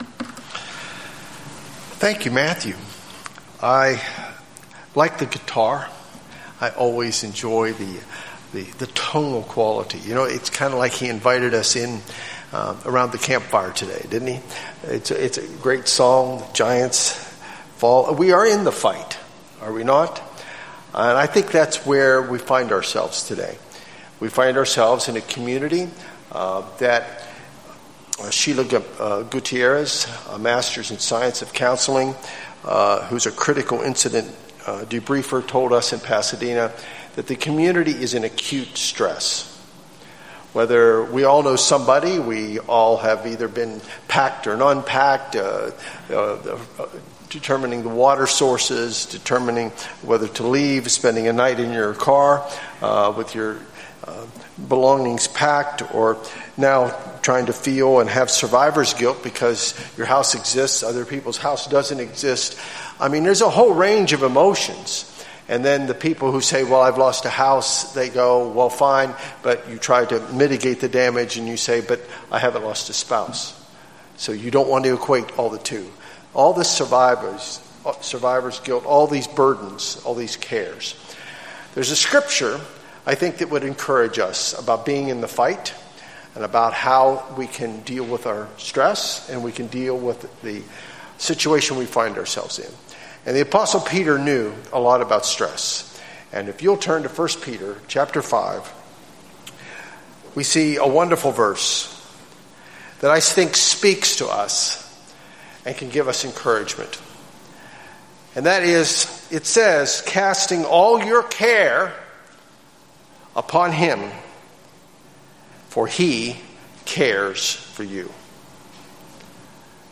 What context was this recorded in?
Given in Los Angeles, CA Bakersfield, CA